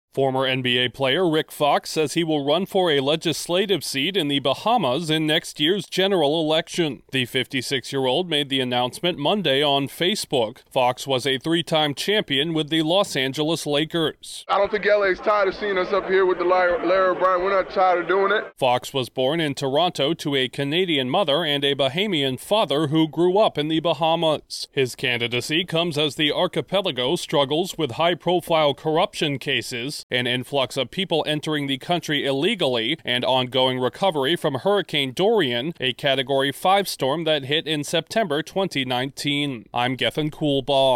A former NBA champ is running for political office in an Atlantic island nation. Correspondent